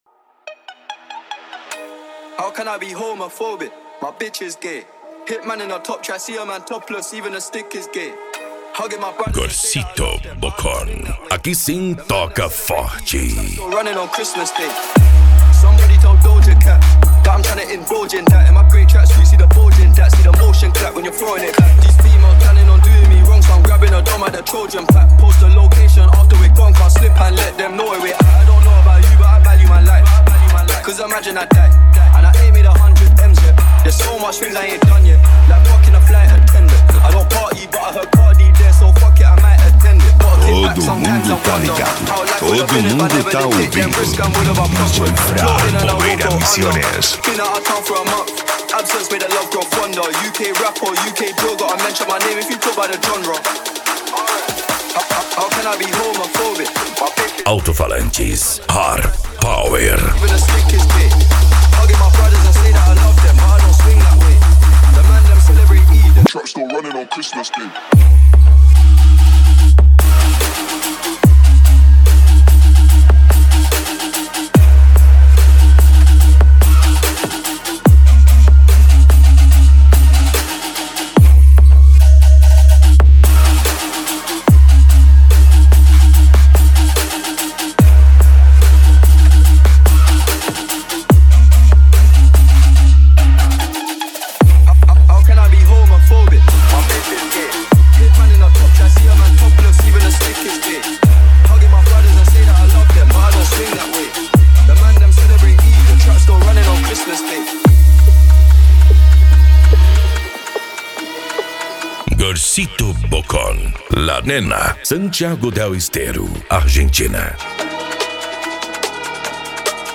Remix
Bass